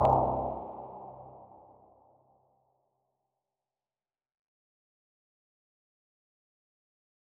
MDMV3 - Hit 9.wav